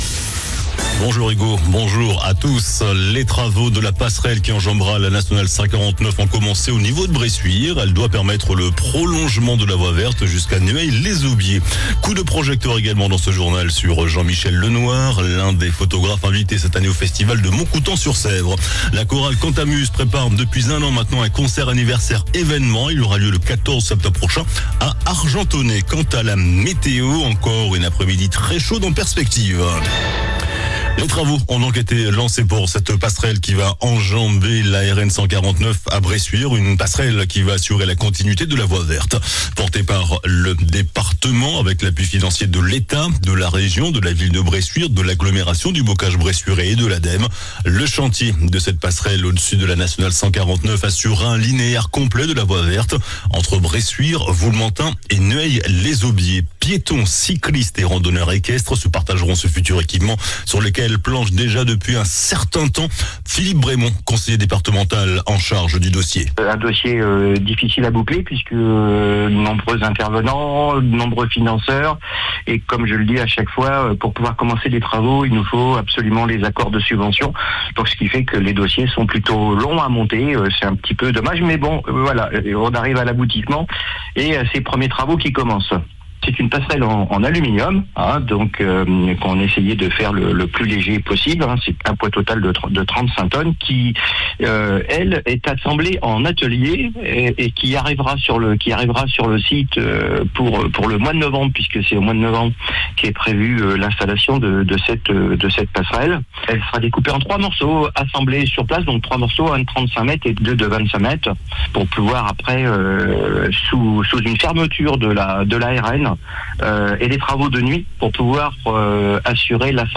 JOURNAL DU MARDI 30 JUILLET ( MIDI )